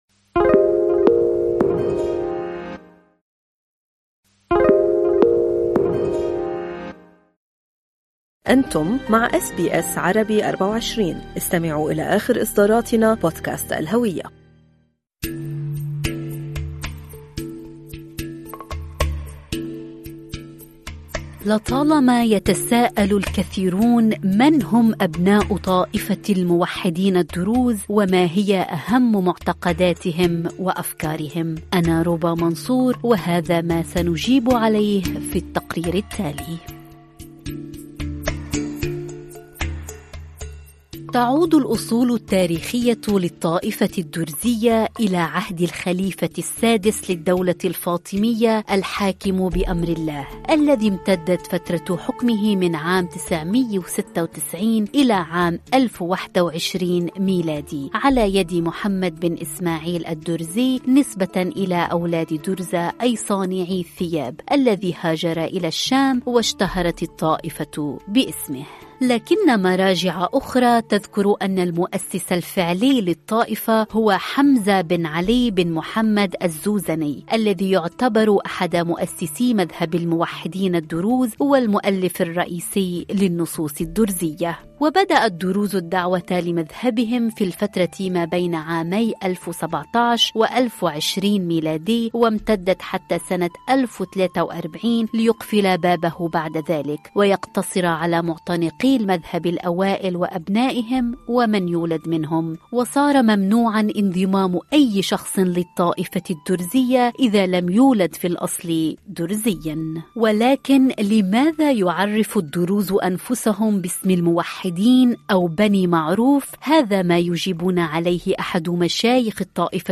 كل الإجابات وغيرها من المعلومات في هذا التقرير.